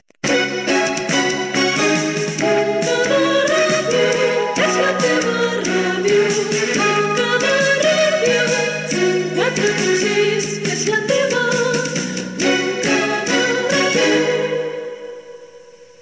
ff548772ecb62ab23668fc45cb52122d6287bba6.mp3 Títol Montcada Ràdio Emissora Montcada Ràdio Titularitat Pública municipal Descripció Indicatiu Data emissió 199?